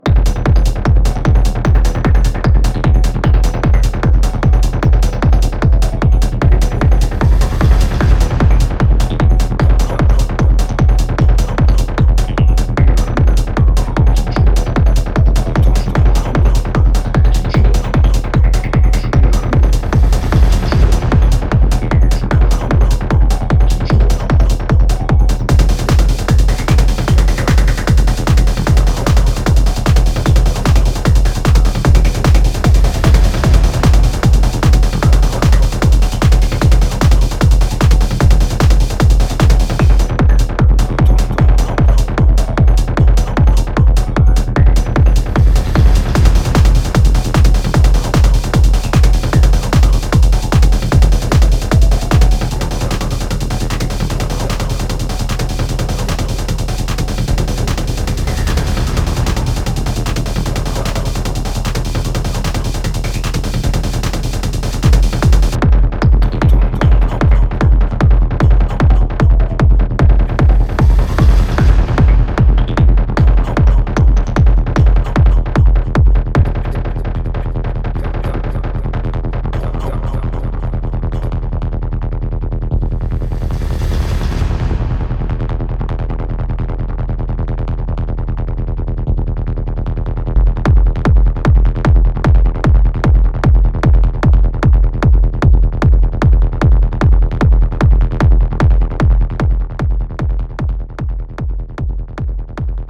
Saturday morning jam